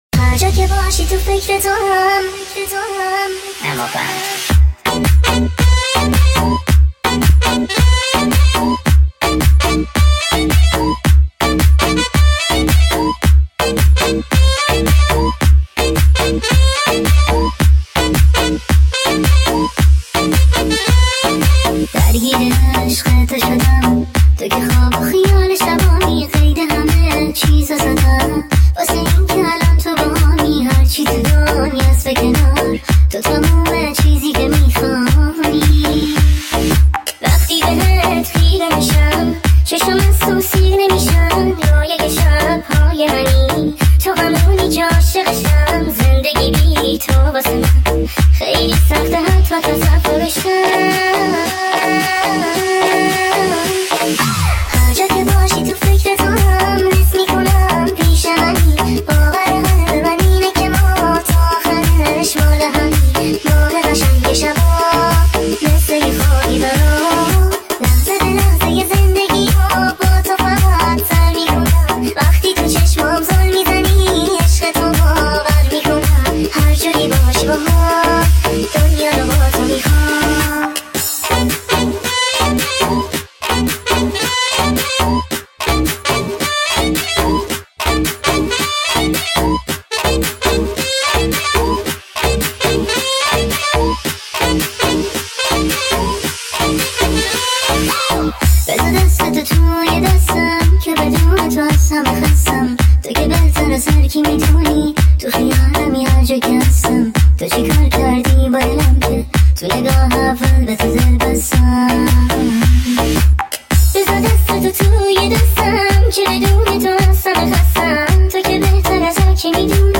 ریمیکس با صدای بچه زن دختر بچگانه دخترانه
آهنگ با صدای بچه